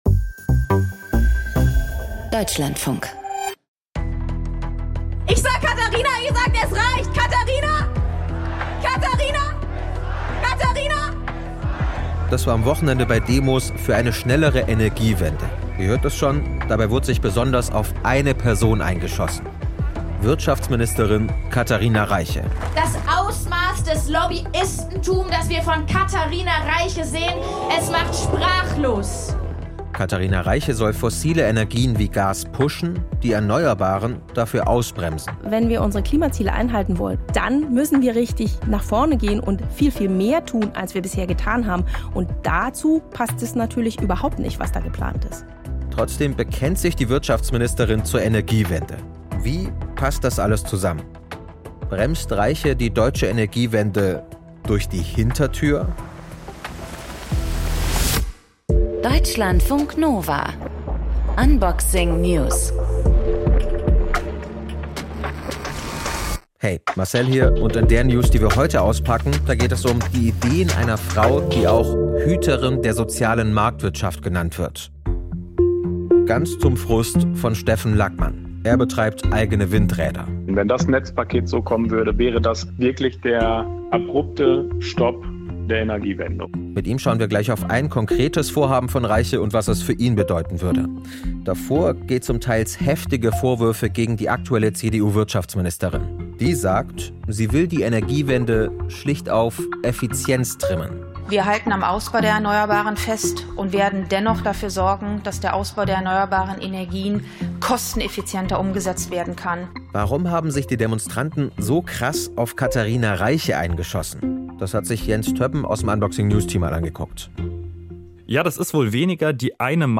Gesprächspartnerin: Luisa Neubauer - Fridays for future